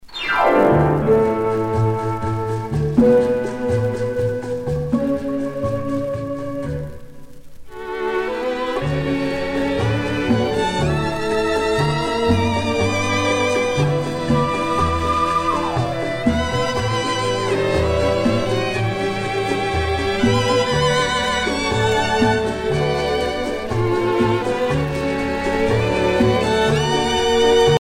danse : rumba